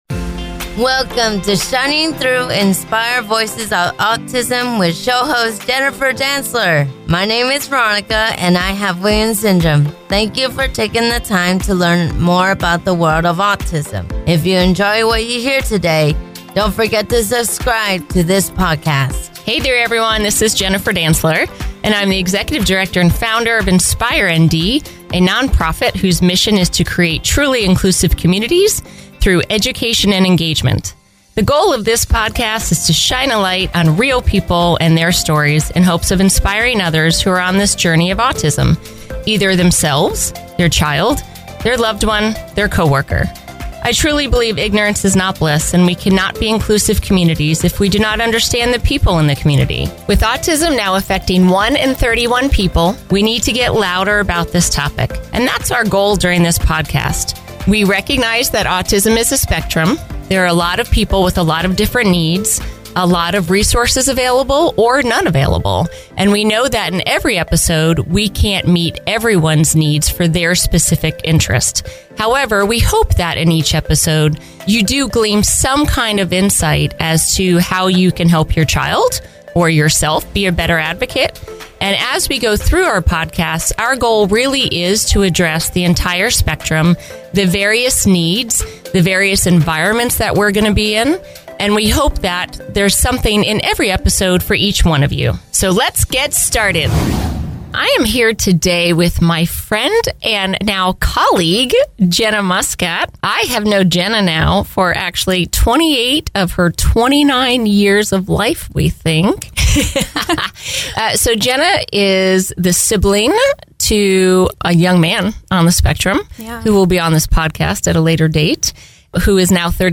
In this powerful conversation